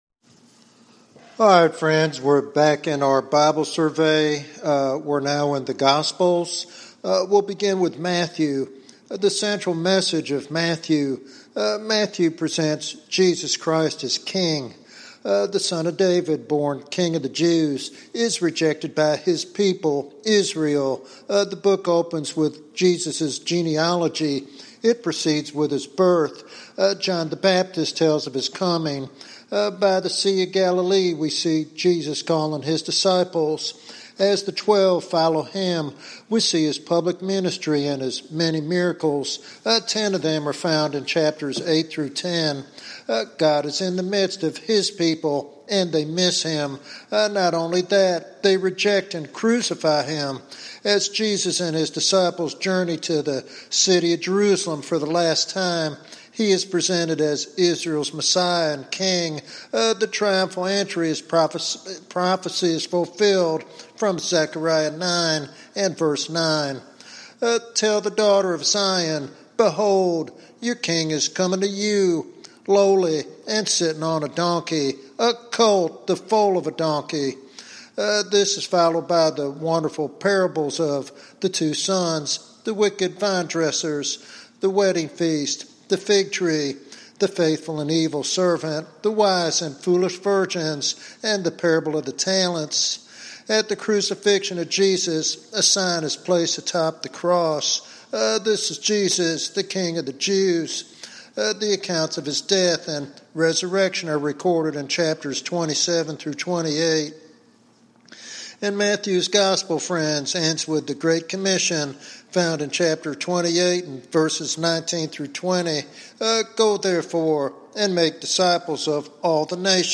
This sermon provides a rich foundation for understanding the person and work of Jesus Christ as presented in the New Testament.